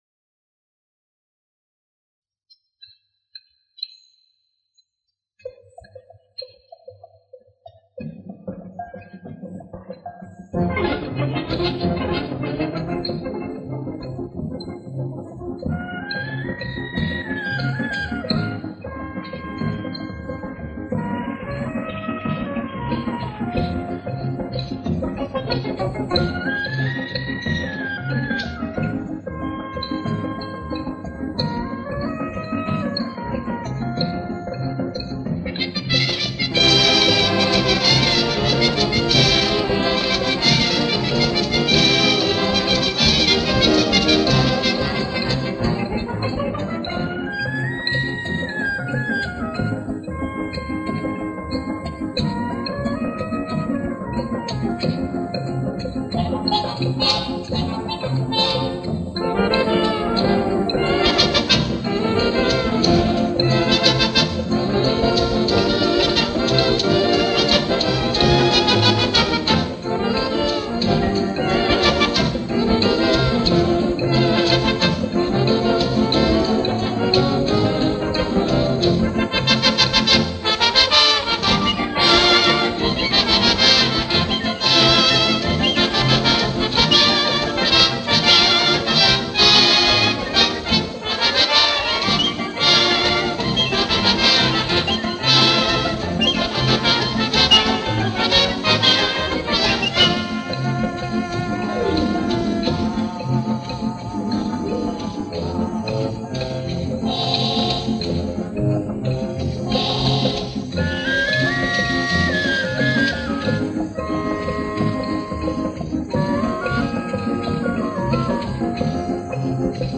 Парочка мелодий с пластинки 1961 года.